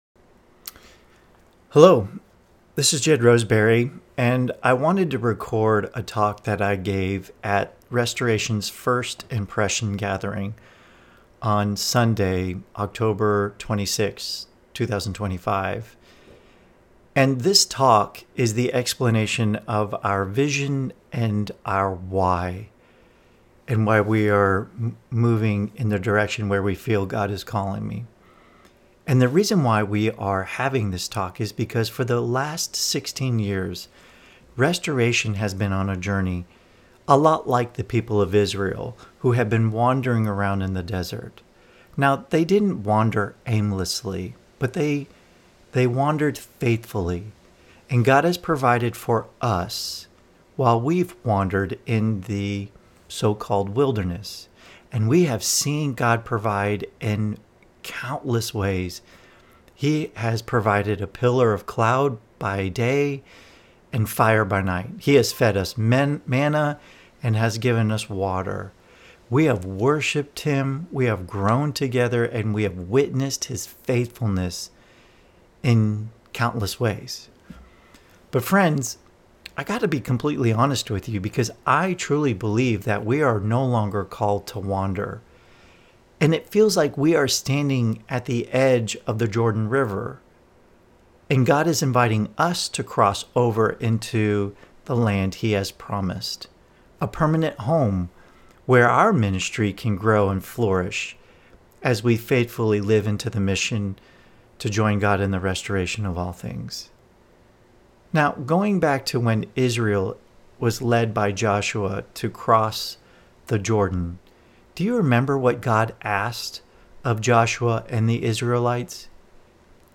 Here is what I shared with all those who attended the First Impressions Gathering about why I believe this is the time for Restoration to move forward with finding a more stable base for ministry in our community.